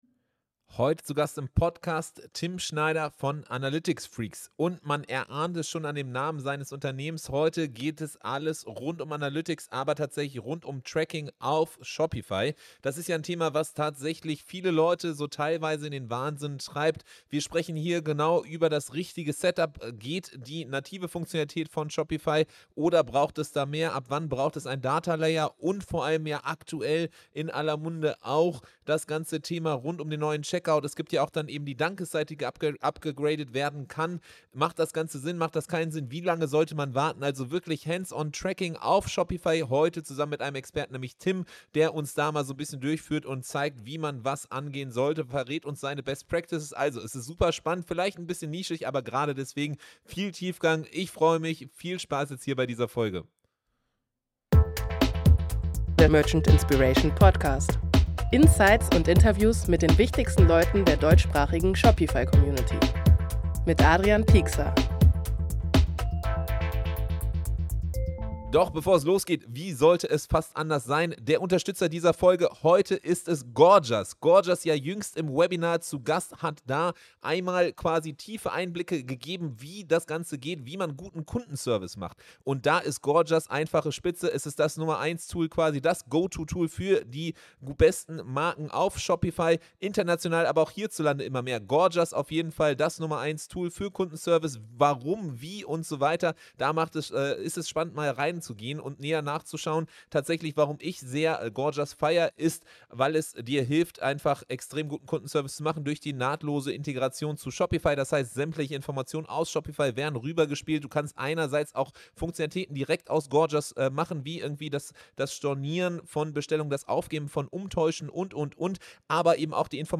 214 – Tracking done right auf Shopify - ein Experte erklärt worauf es ankommt… ~ Merchant Inspiration: Podcast für Shopify Händler Podcast